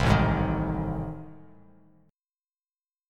AmM7b5 chord